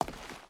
Footsteps / Stone / Stone Walk 4.wav
Stone Walk 4.wav